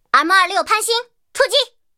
M26潘兴出击语音.OGG